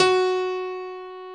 55q-pne01-F#3.wav